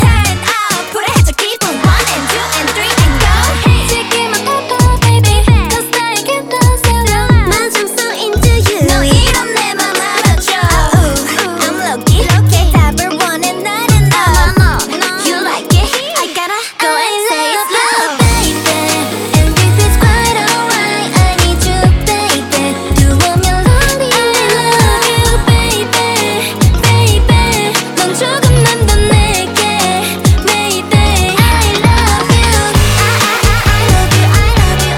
K-Pop Pop
2025-08-01 Жанр: Поп музыка Длительность